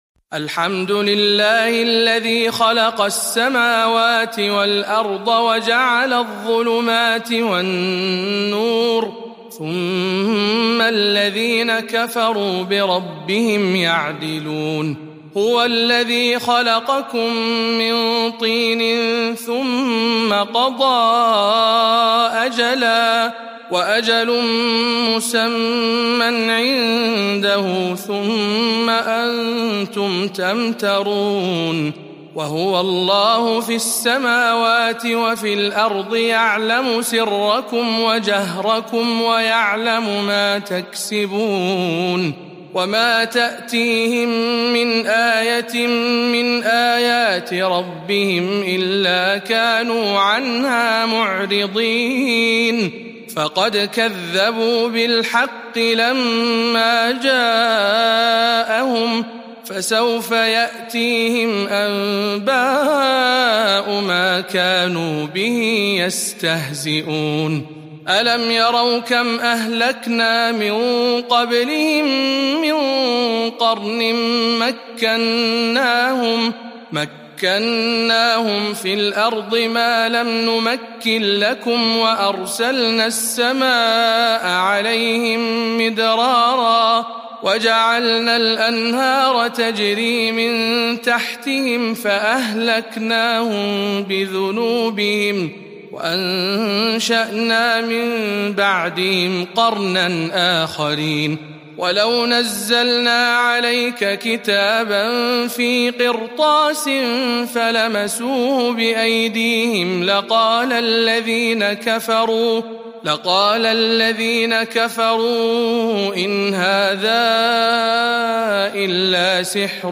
سورة الأنعام برواية شعبة عن عاصم